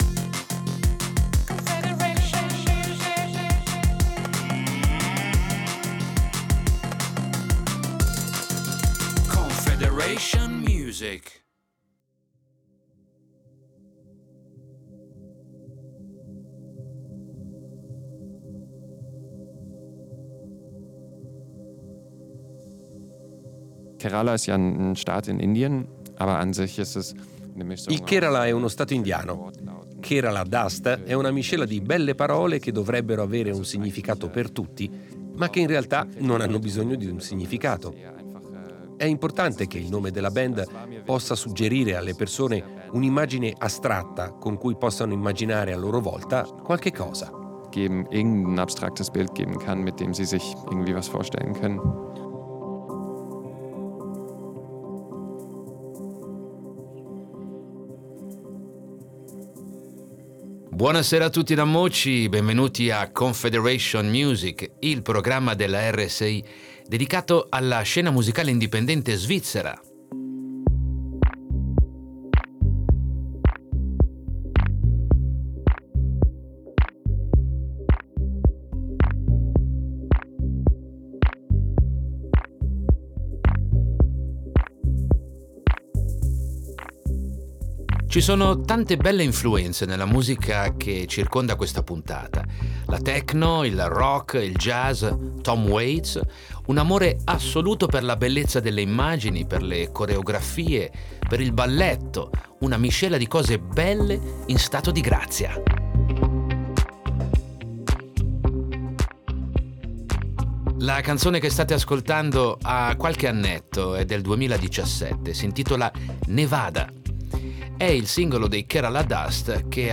Insieme formano progetto anglo-svizzero Kerala Dust, una magica entità elettronica e acustica ispirata dalla techno, dal rock, dal jazz, dai Can e da Tom Waits.
Contiene il caldo torrido del deserto e il groove della techno.